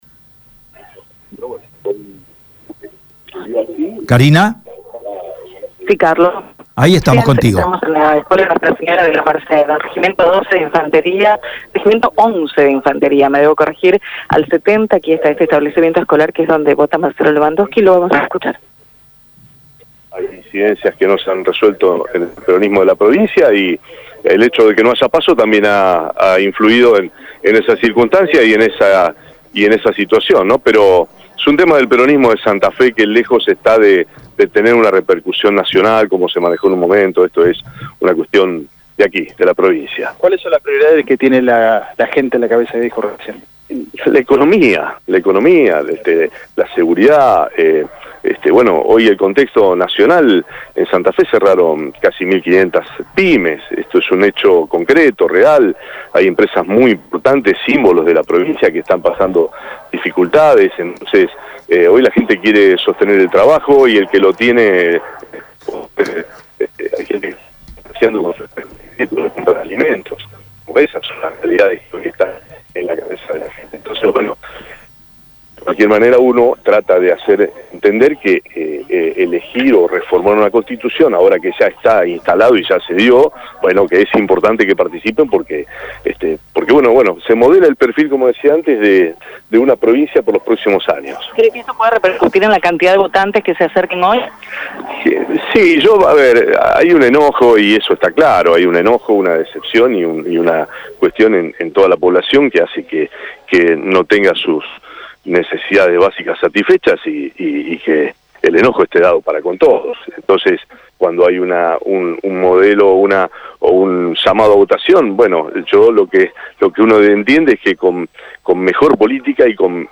El diputado nacional y candidato a convencional constituyente por el espacio Activemos, Marcelo Lewandovsky, emitió su voto este domingo en la Escuela Nuestra Señora de la Merced, en Rosario.